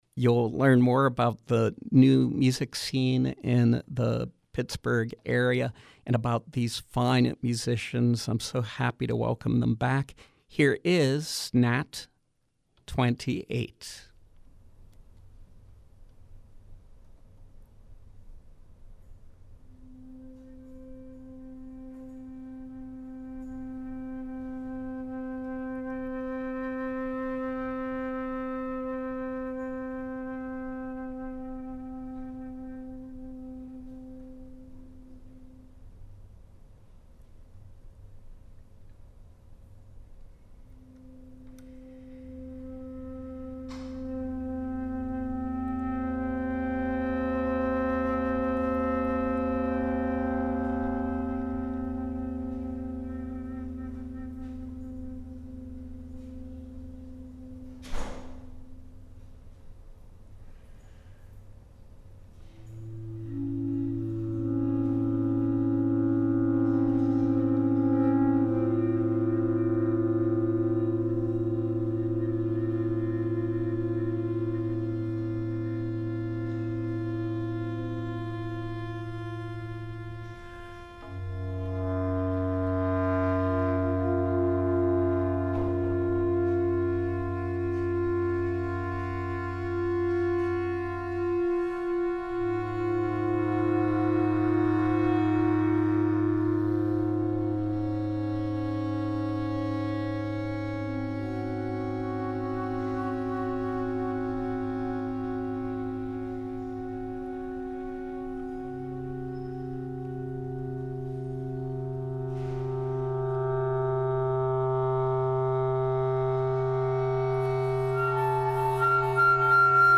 Live performance with musicians